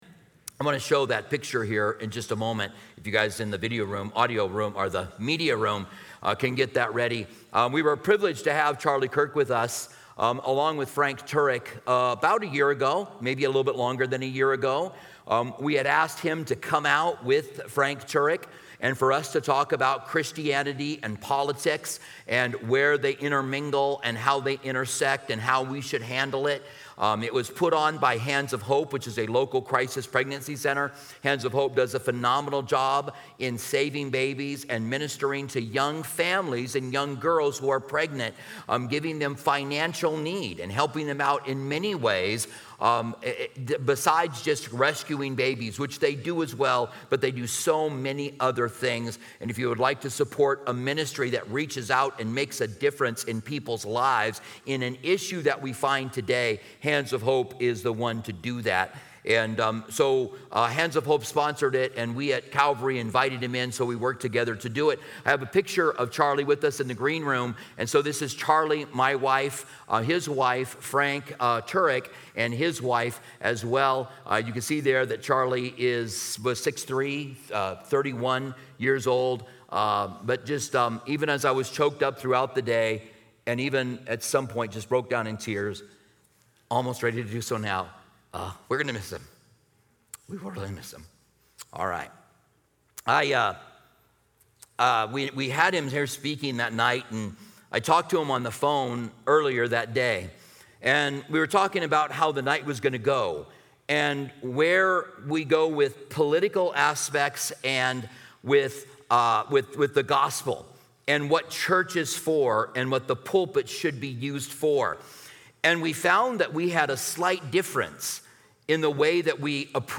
Special Messages